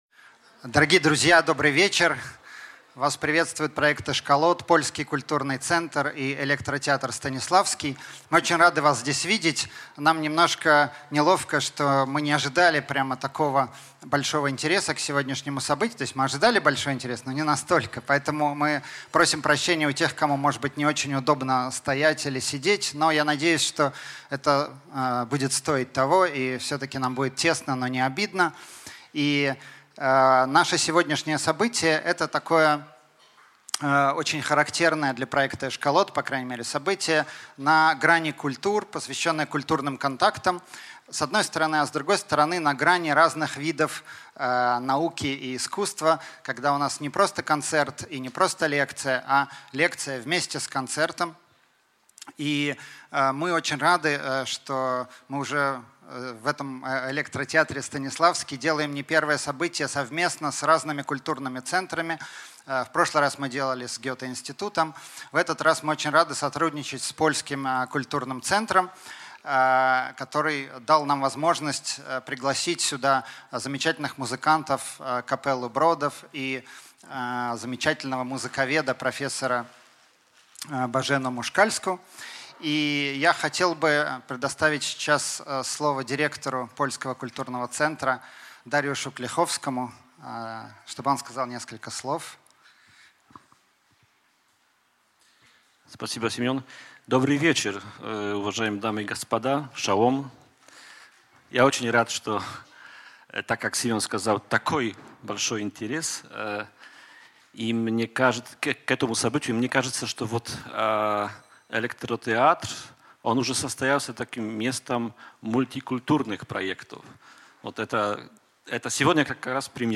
Аудиокнига Клезмерская музыка в памяти деревенских музыкантов | Библиотека аудиокниг